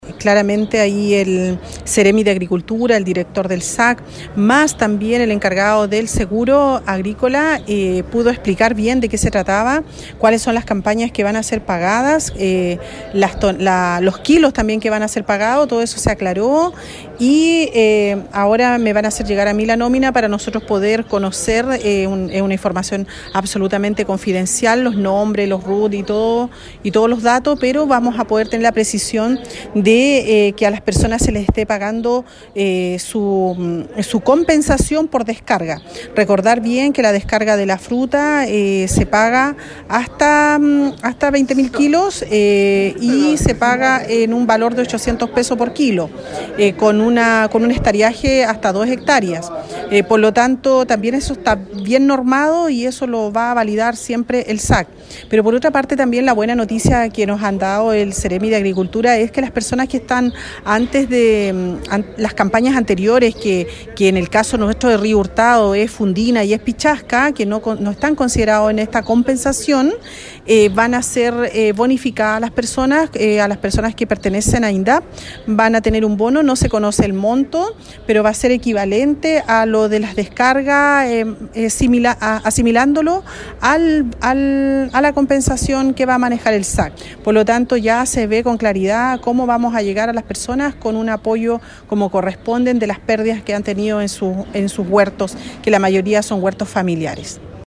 La alcaldesa Carmen Juana Olivares cuenta en qué consistió la reunión que se realizó en la Delegación Presidencial Provincial, ubicada en Ovalle.